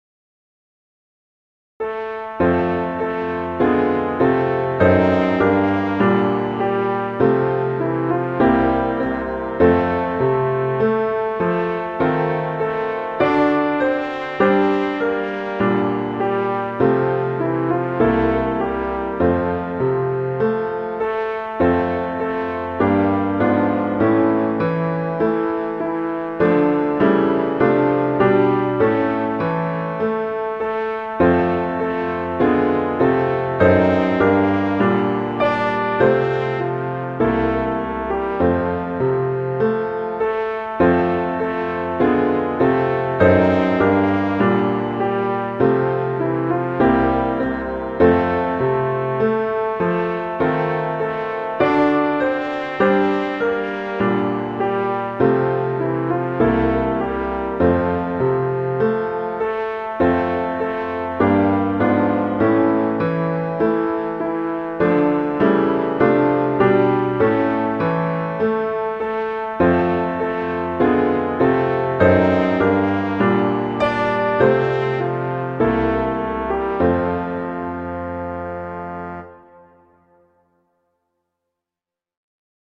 trombone and piano